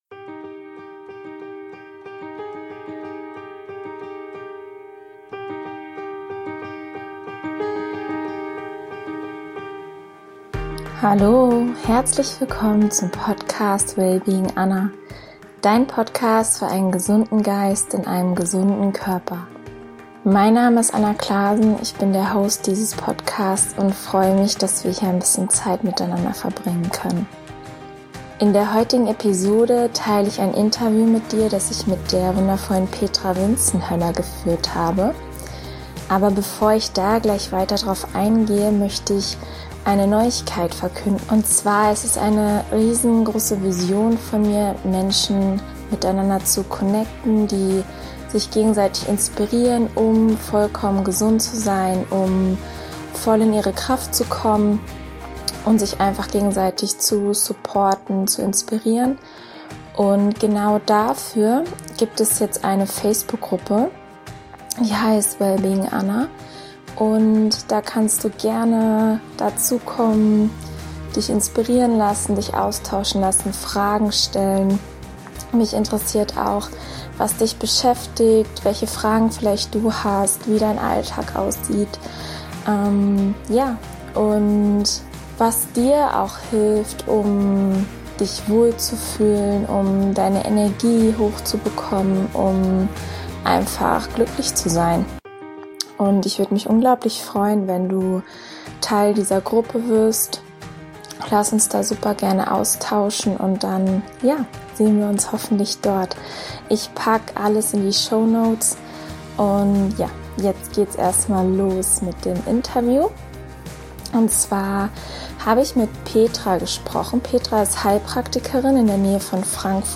In der heutigen Episode teile ich mit dir ein wundervolles Gespräch mit der inspirierenden